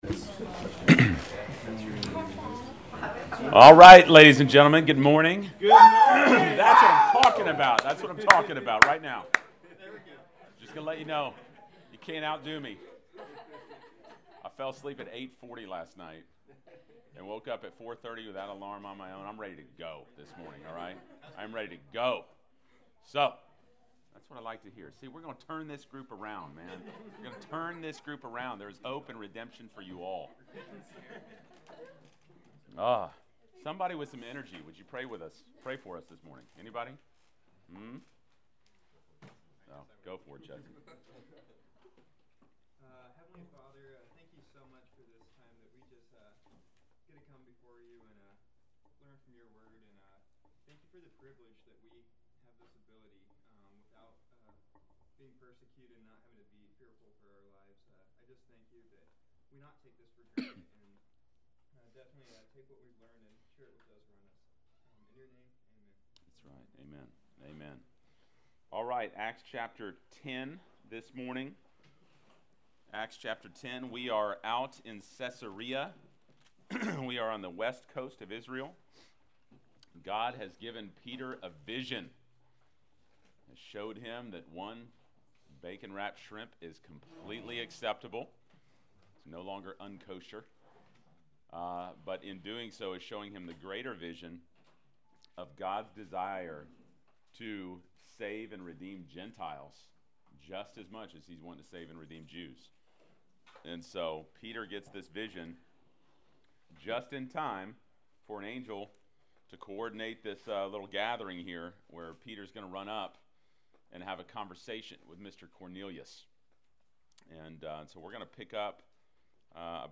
Class Session Audio November 03